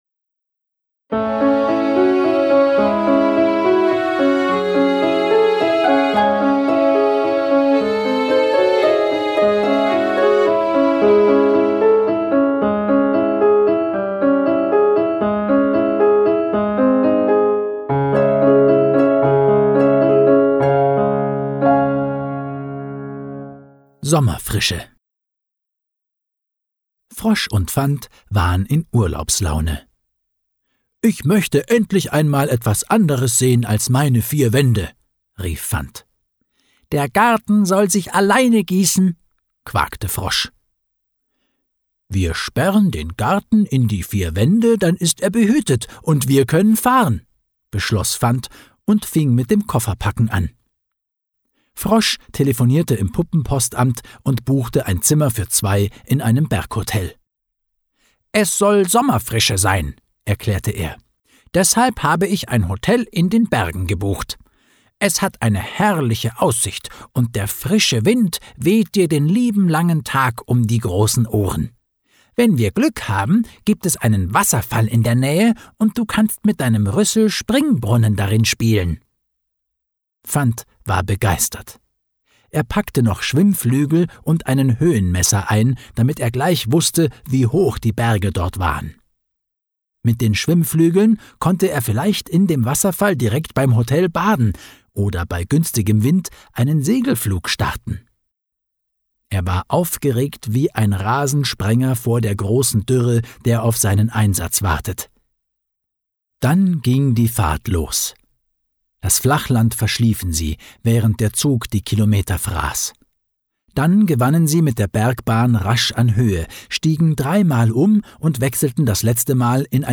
Schlagworte Freundschaft • Frosch • Herbst • Kinderhörbuch • Lesegeschichten • Urlaub